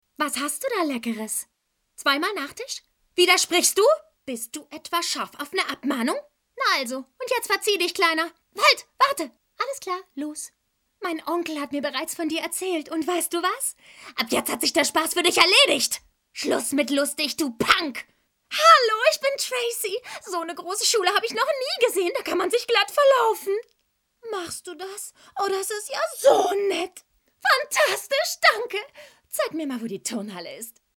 Sprechprobe: eLearning (Muttersprache):
Actress, Dubbing, Advertisement, Games, Audio Drama, Voice-Over, Native Speaker (German), English (US), Warm, Feminine, Sensitive, Clear, Laid-Back, Cool, Young, Fresh, Hip, Charming, Seductive, Provoking, Challenging, Dark, Mad, Angry, Wicked, Emotional, Understatement, Comical, Funny, Comic-Voice, Playful